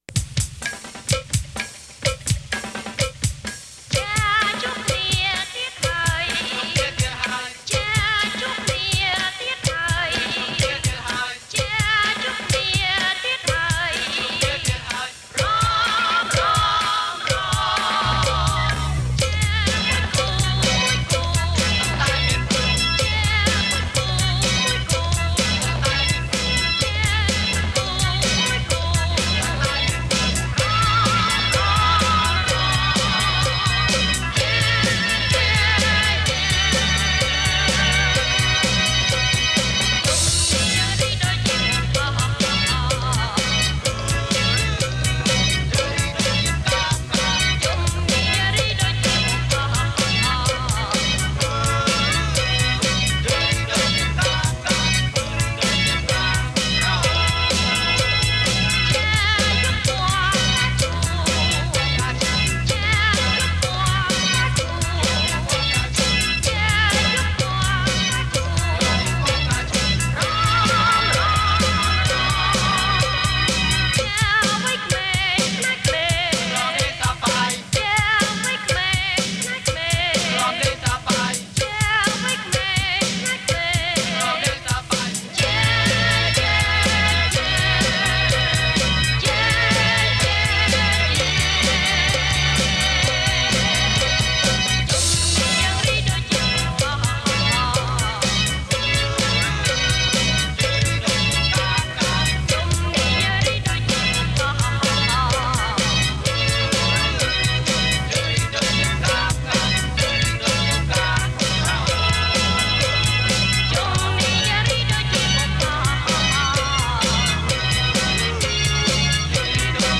International Pop Rock